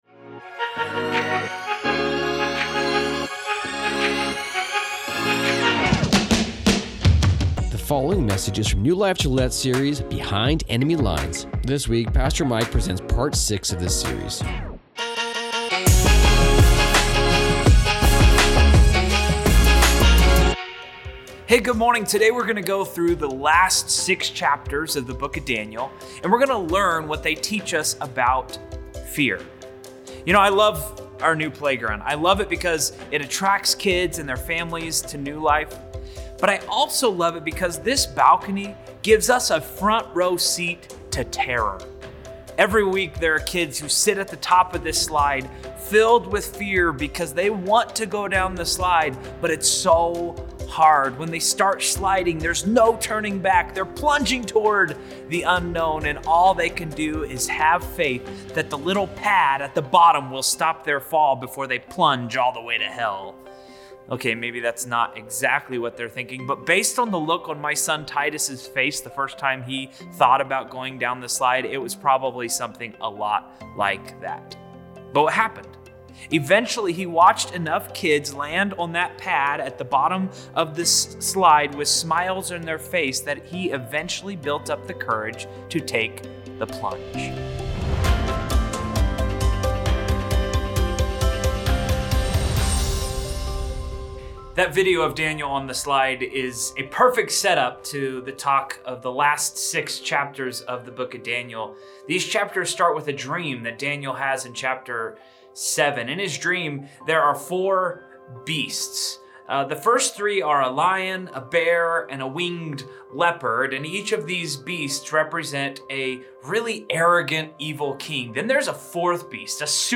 New Life Gillette Church Teachings